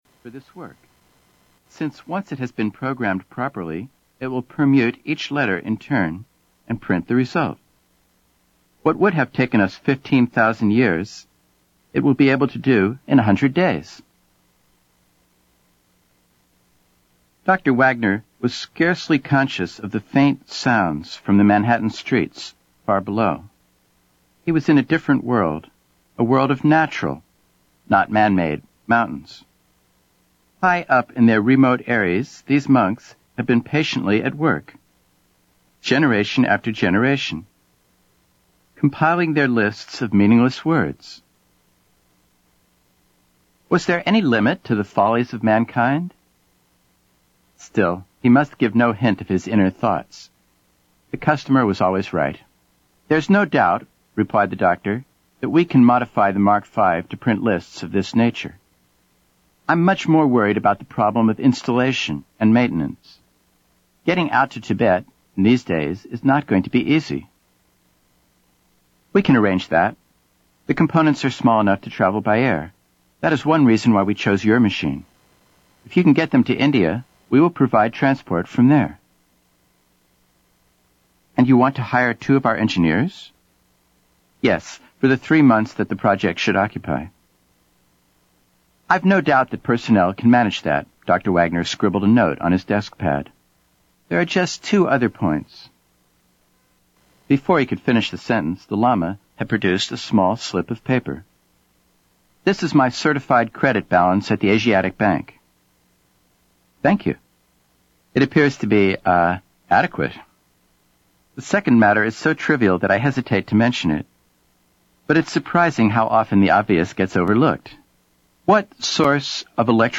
Tags: Philip K Dick Audiobooks Philip K Dick Philip K Dick Audio books Scie-Fi Scie-Fi books